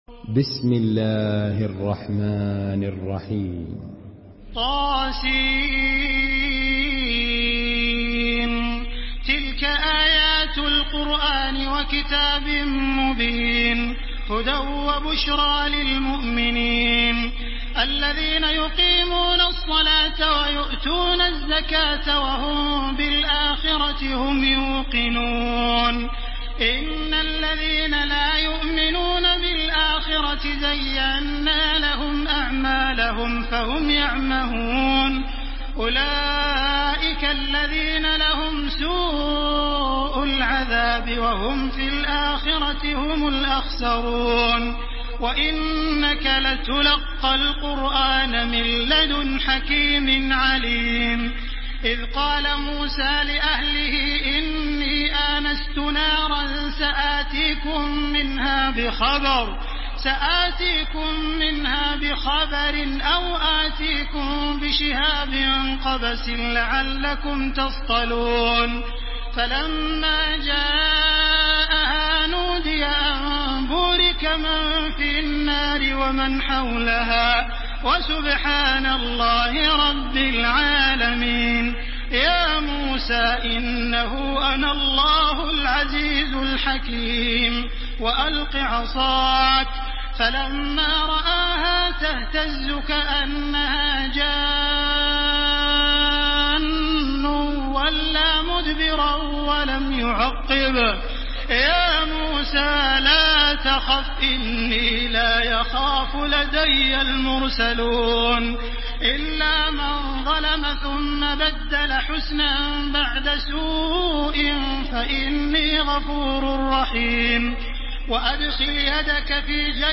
تحميل سورة النمل بصوت تراويح الحرم المكي 1430
مرتل